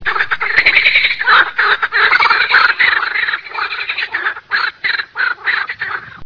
Ascolta il gracidare
di Rana kl. esculenta
rane.rm